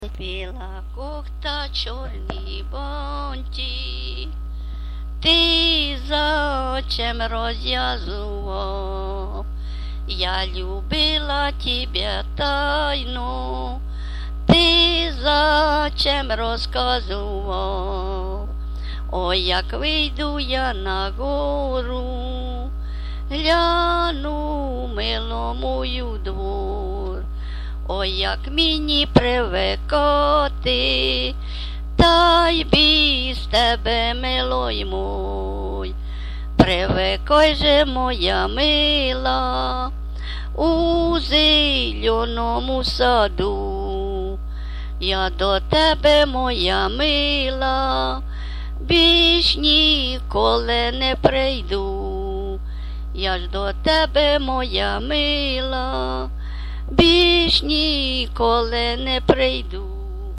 ЖанрПісні з особистого та родинного життя, Сучасні пісні та новотвори
Місце записус. Нижні Рівні, Чутівський район, Полтавська обл., Україна, Слобожанщина